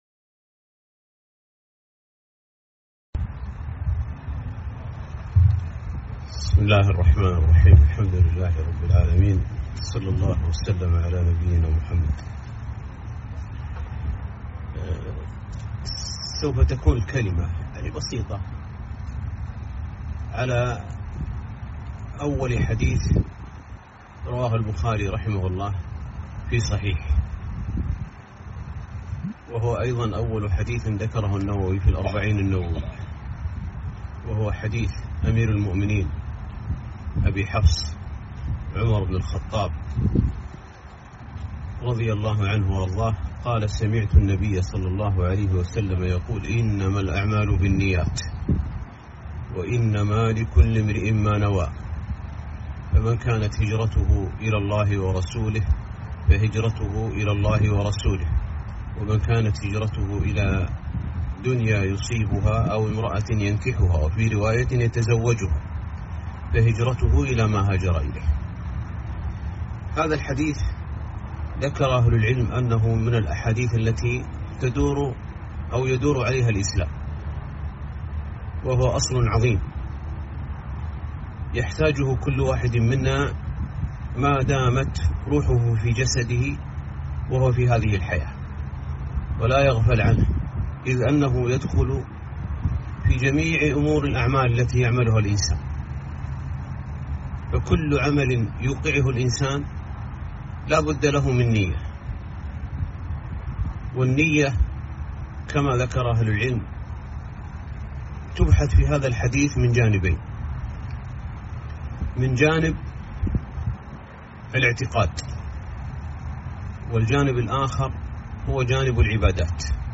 محاضرة - (إنما الأعمال بالنيات)